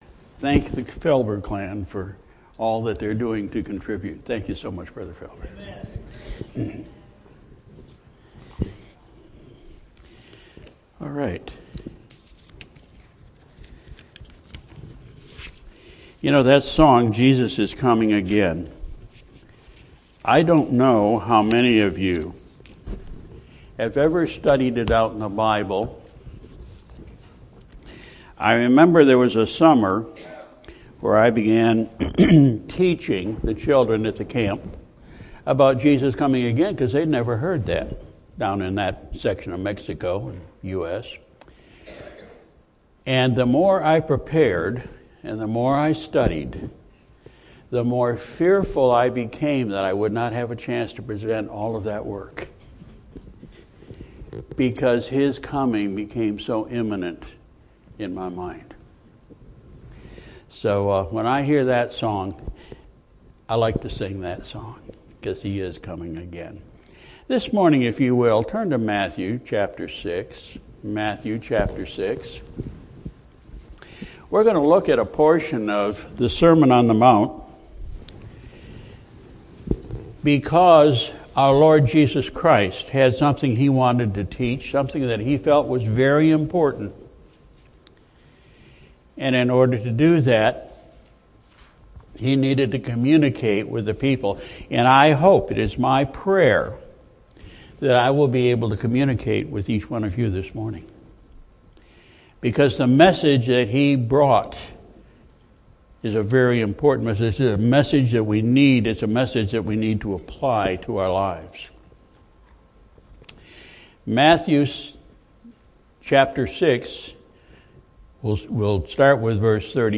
Our morning message was based on Matthew 6:25-34. We learned that our life purpose should be to obey God.